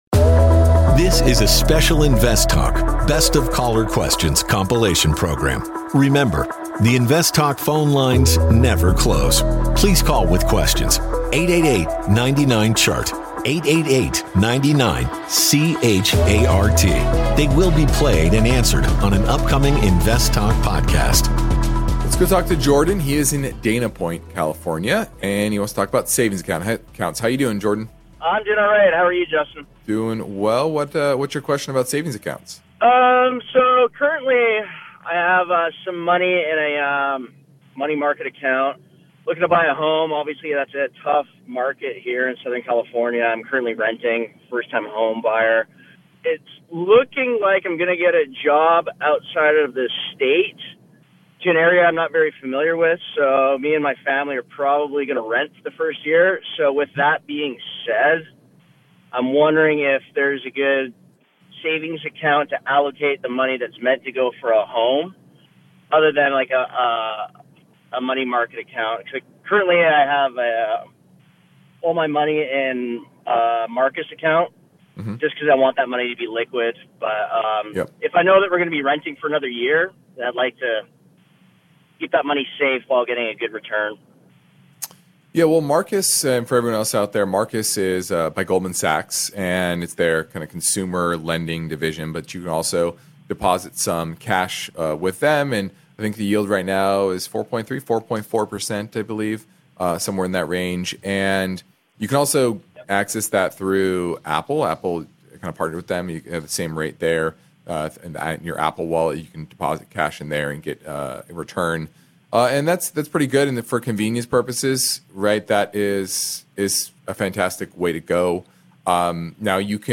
Best of Caller Questions